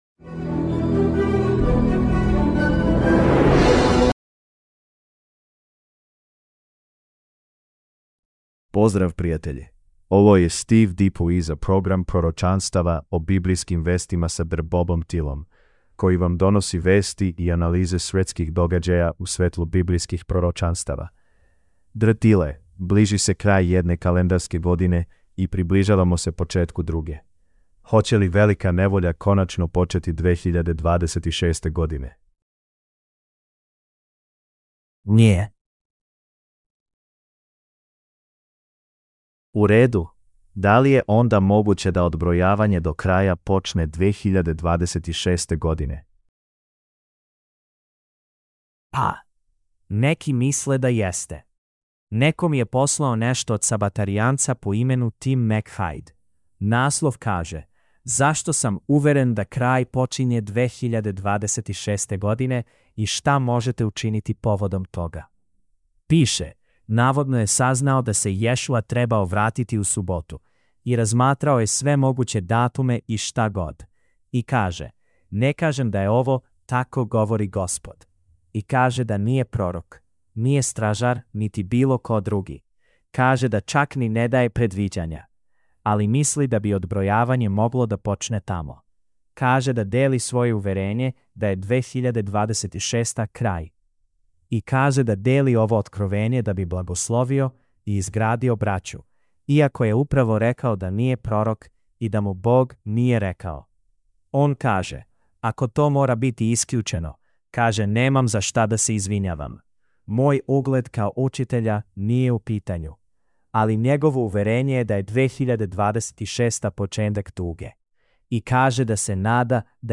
Serbian Sermonette – Bible Prophecy News